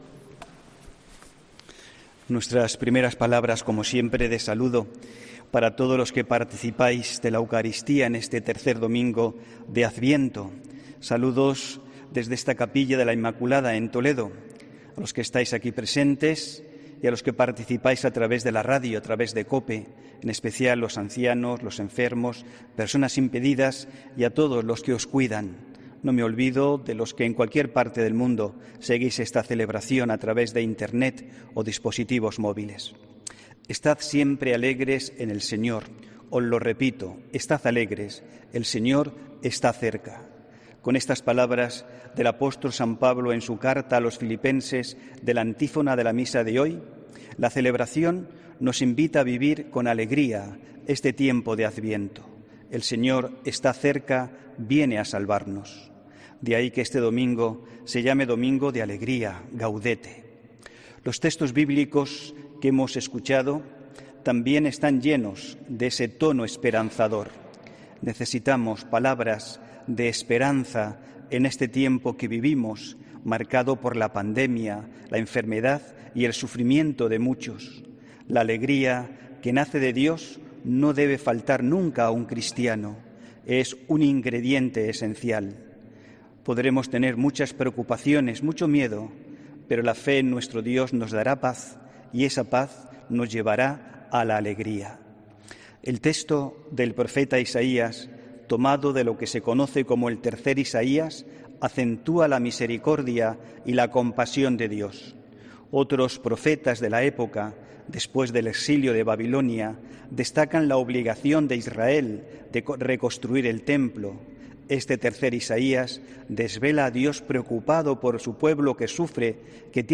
HOMILÍA 13 DICIEMBRE 2020